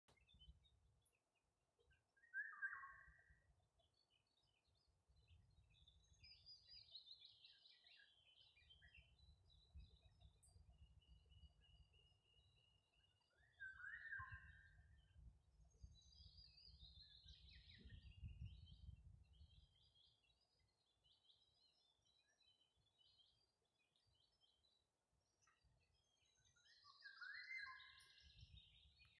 иволга, Oriolus oriolus
СтатусПоёт